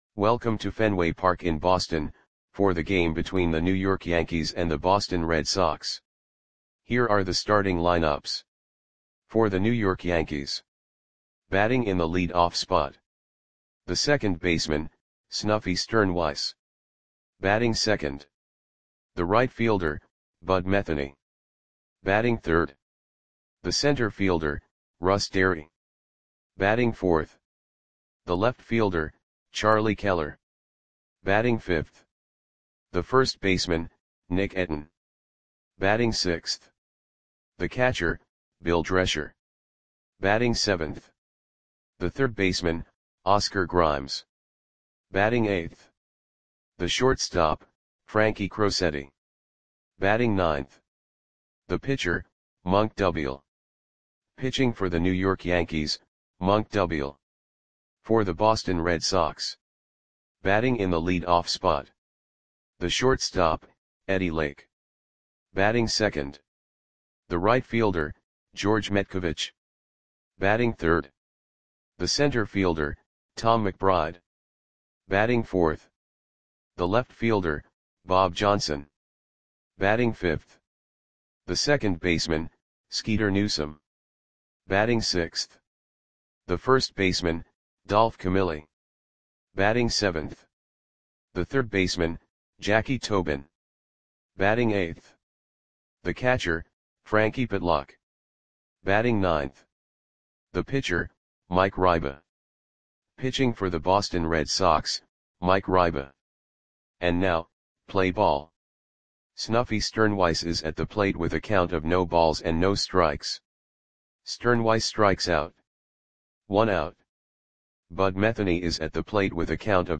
Audio Play-by-Play for Boston Red Sox on September 22, 1945
Click the button below to listen to the audio play-by-play.